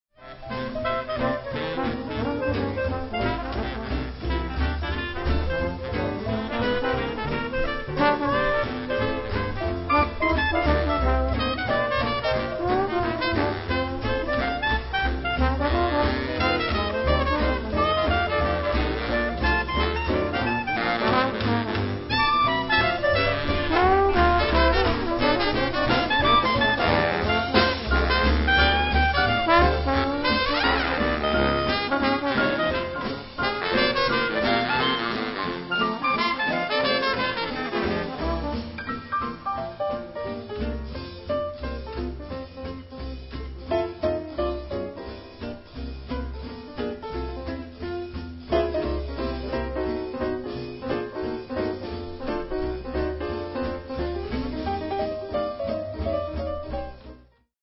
cornetta
trombone
clarinetto
pianoforte
contrabbasso
batteria